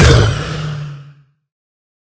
sounds / mob / wither / hurt2.ogg
hurt2.ogg